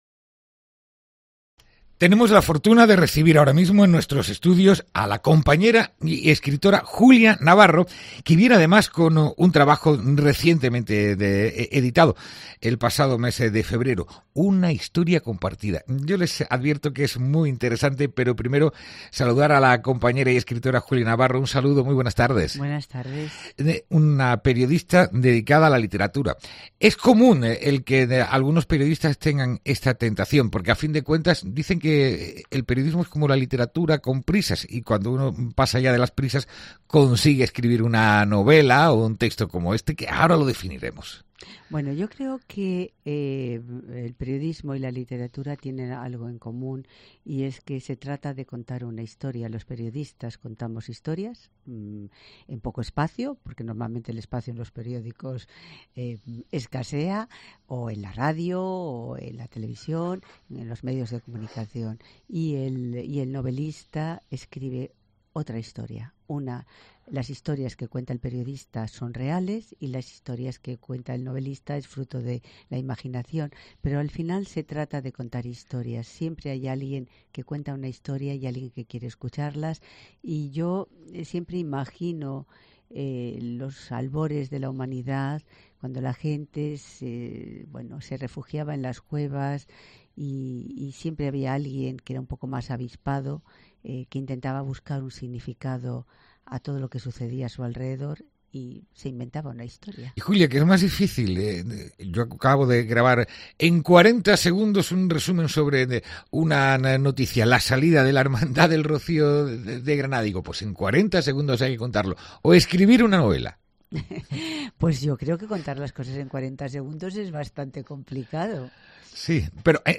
ENTREVISTA|| Julia Navarro presenta en COPE su último libro: Una historia compartida